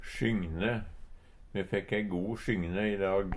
sjygne - Numedalsmål (en-US)